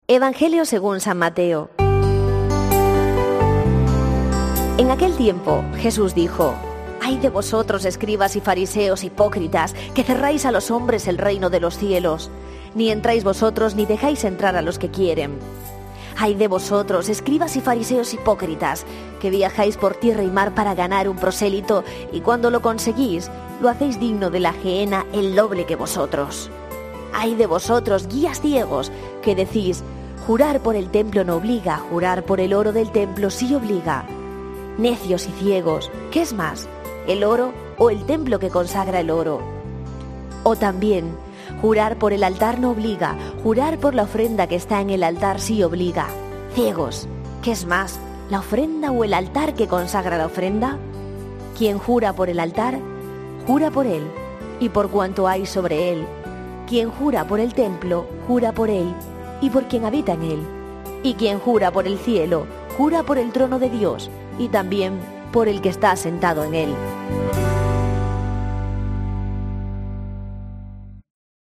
Lectura del santo evangelio según san Mateo 23,13-22